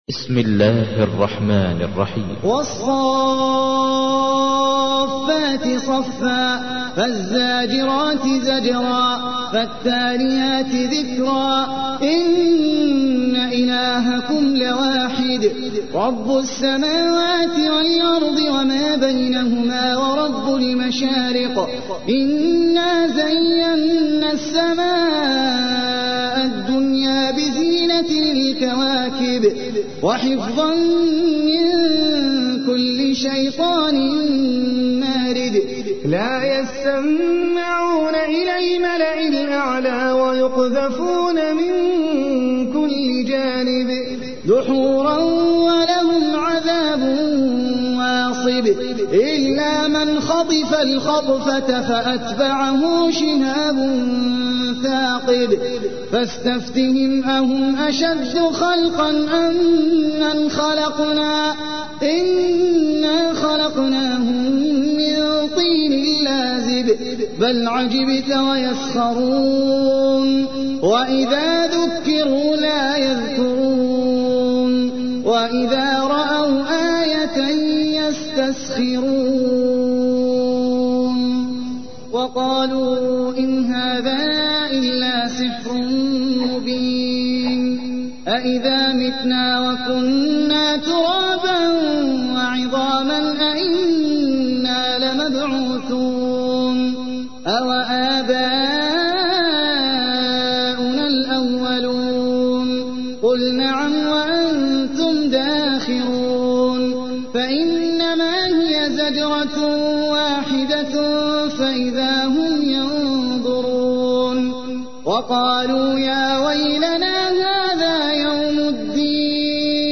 تحميل : 37. سورة الصافات / القارئ احمد العجمي / القرآن الكريم / موقع يا حسين